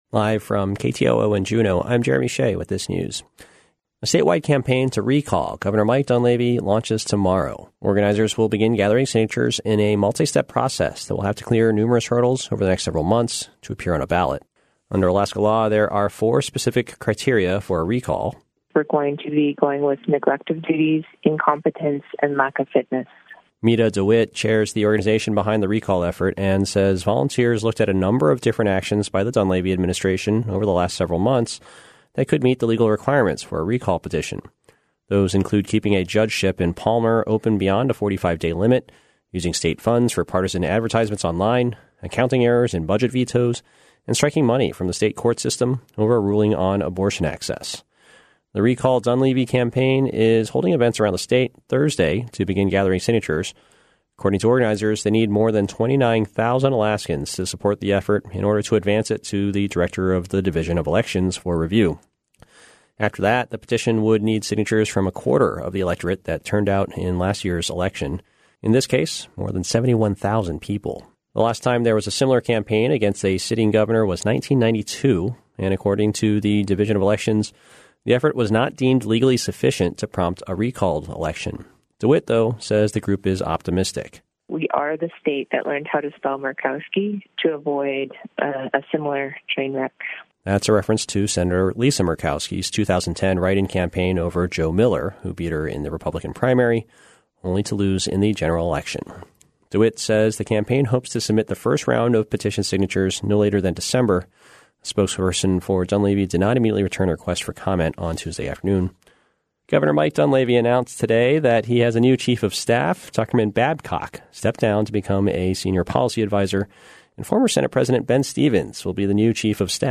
Newscast – Wednesday, July 31, 2019